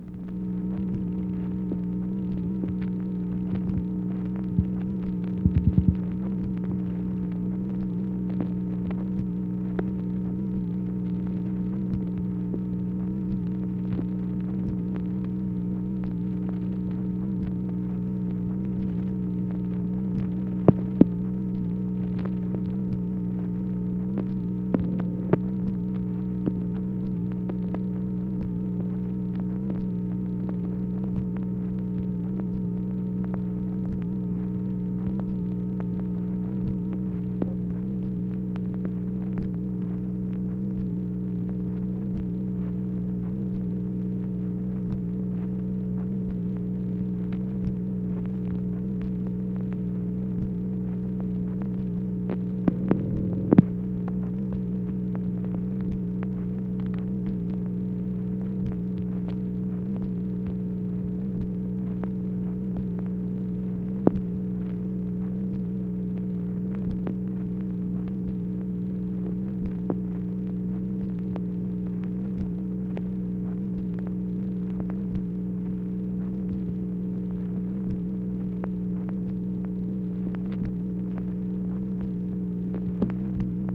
MACHINE NOISE, January 29, 1964
Secret White House Tapes | Lyndon B. Johnson Presidency